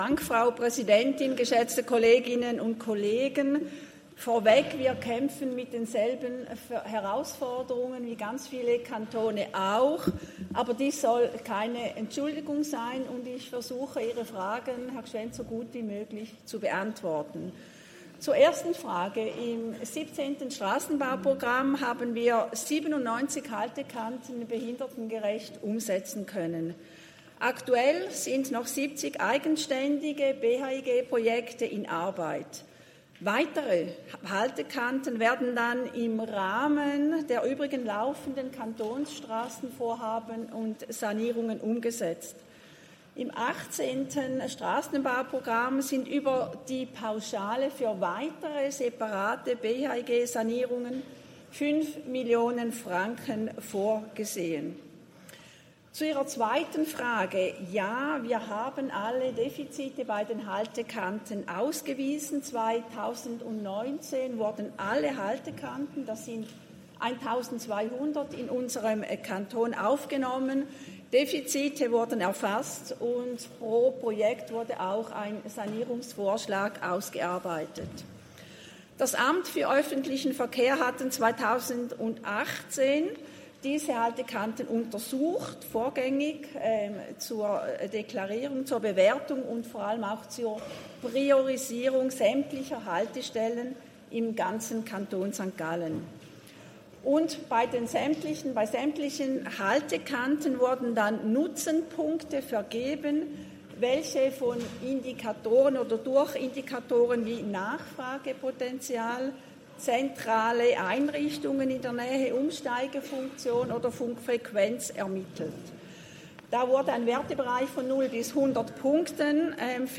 Session des Kantonsrates vom 3. und 4. Juni 2024, Sommersession
3.6.2024Wortmeldung